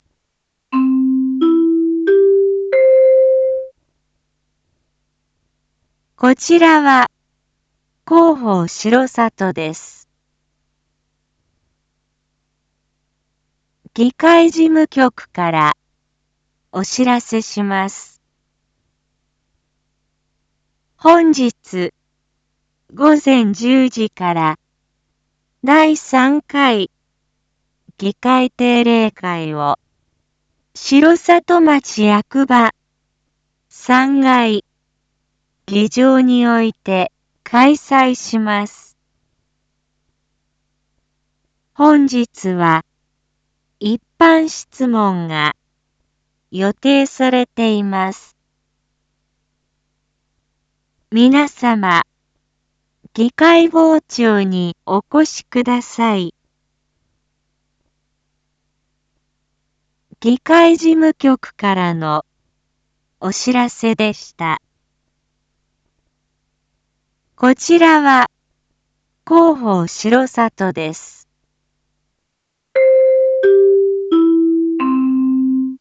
一般放送情報
Back Home 一般放送情報 音声放送 再生 一般放送情報 登録日時：2023-09-13 07:01:17 タイトル：9/13 7時 第3回議会定例会 インフォメーション：こちらは広報しろさとです。